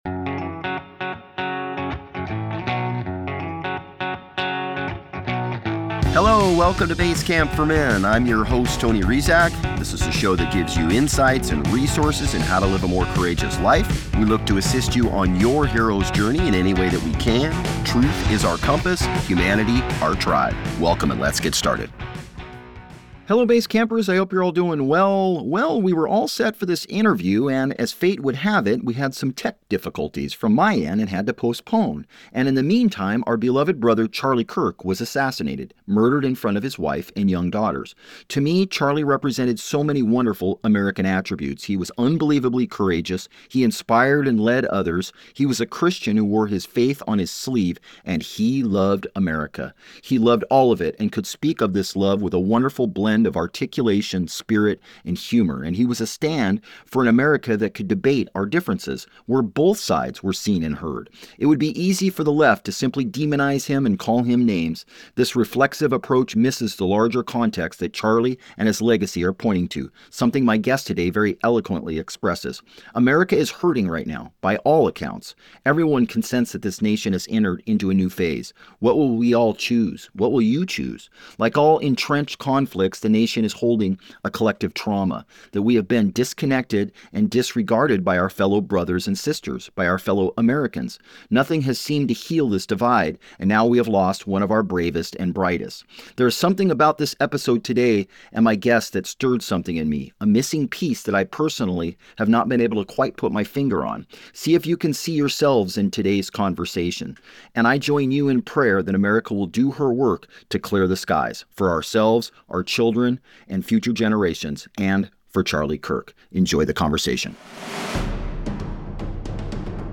Well, we were all set for this interview and, as fate would have it, we had some tech difficulties (from my end) and had to postpone.